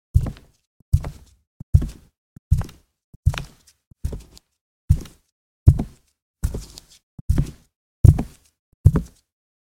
Download Free Footsteps Sound Effects | Gfx Sounds
Socks-walk-on-wooden-surface.mp3